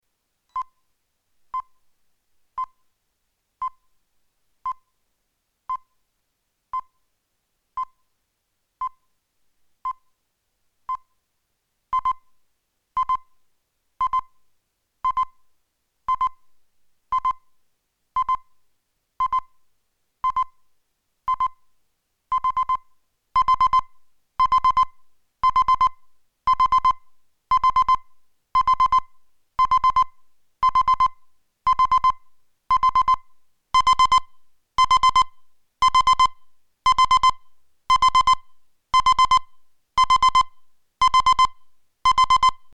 With vibration unit, extra-loud alarm sound and flashing light.
Volume, tone pitch and brightness level adjustable. Two separate alarms with different alarm sounds.